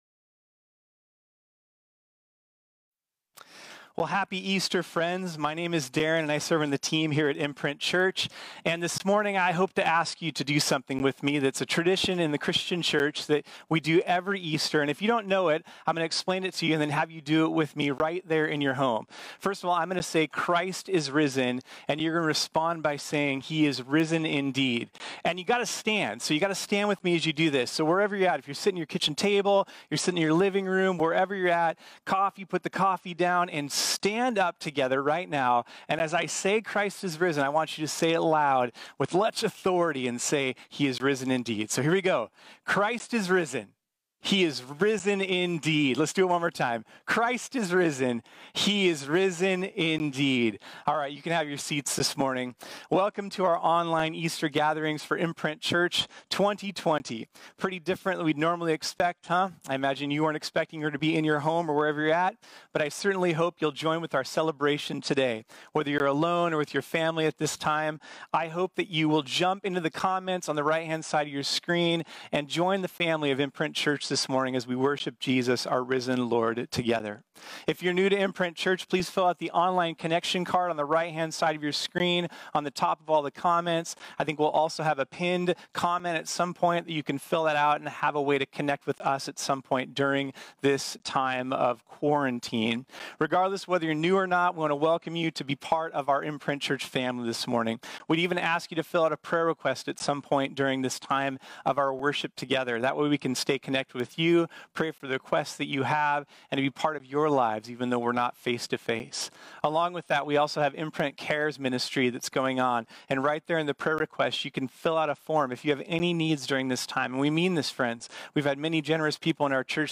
This sermon was originally preached on Sunday, April 12, 2020.